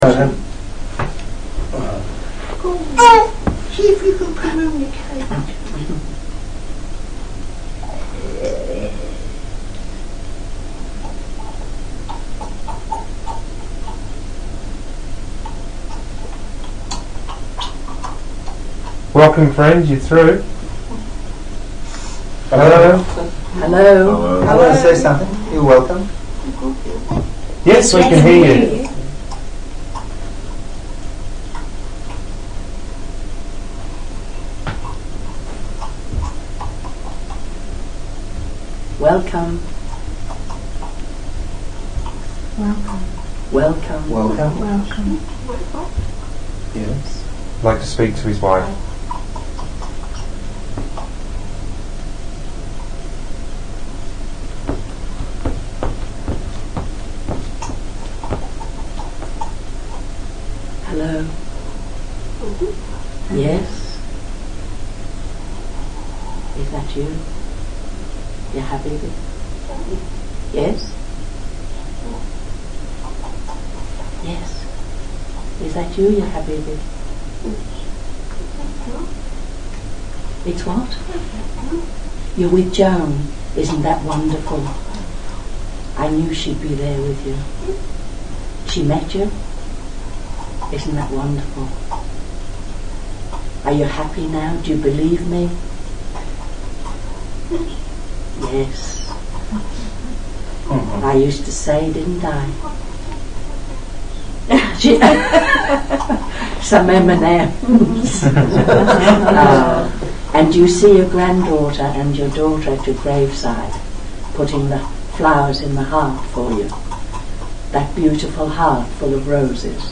registrazione (clic) lo si può sentir muovere a fatica attraverso la stanza e produrre uno strano rumore succhiante, che la moglie ha confermato che emetteva durante i suoi ultimi giorni di vita terrena quando soffriva di Alzheimer.